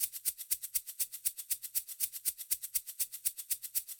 31 Shaker.wav